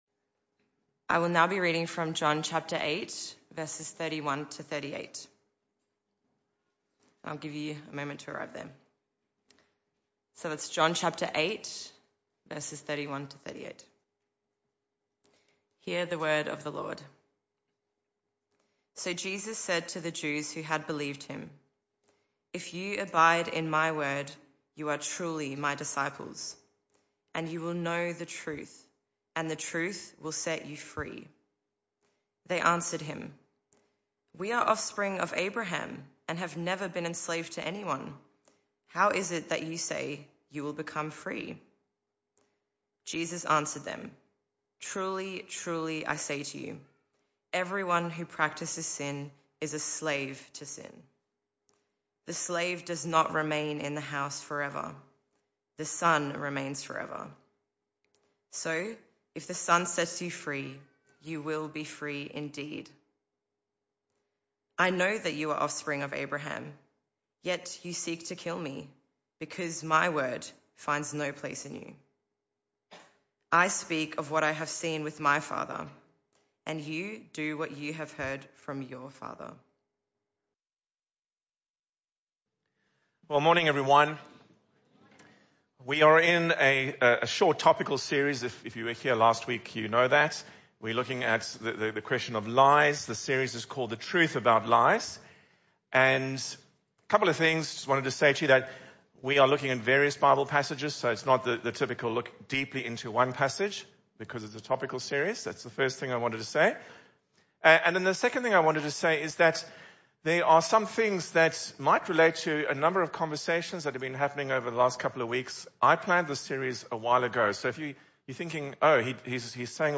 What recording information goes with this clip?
This talk was part of the AM Service series entitled The Truth About Lies. John 8:31-38 Service Type: Morning Service This talk was part of the AM Service series entitled The Truth About Lies.